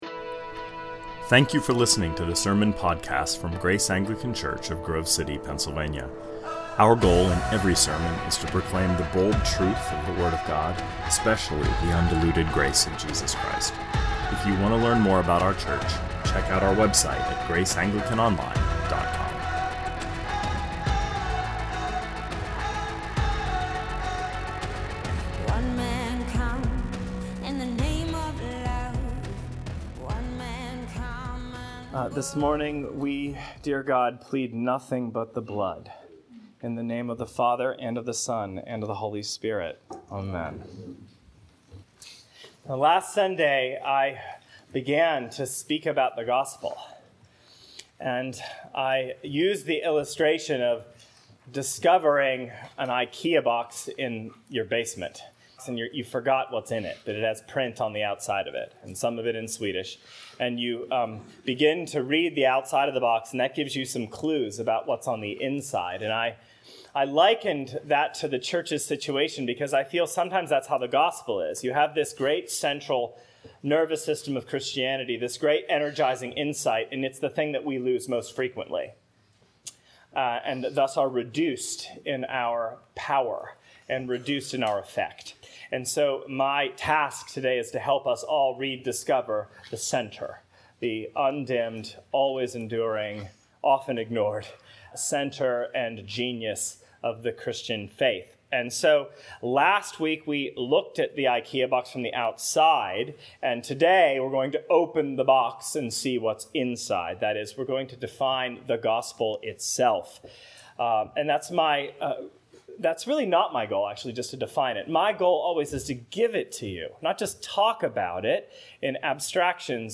2019 Sermons